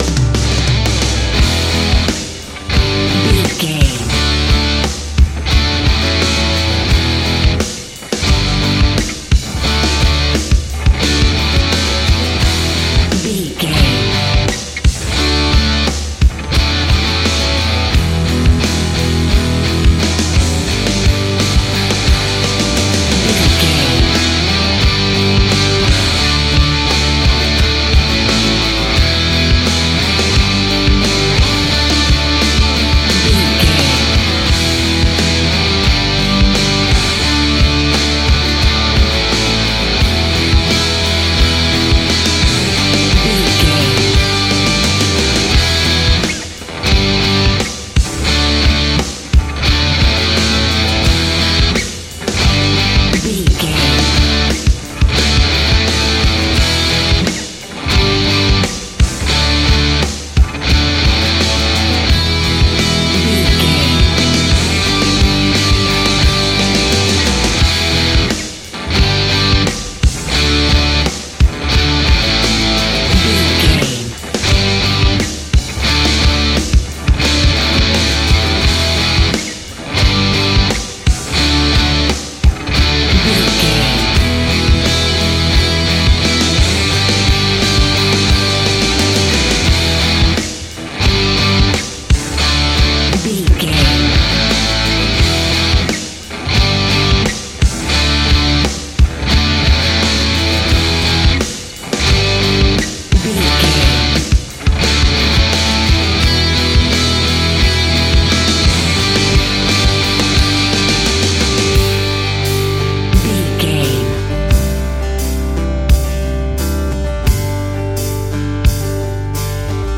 Ionian/Major
electric guitar
drums
acoustic guitar
bass guitar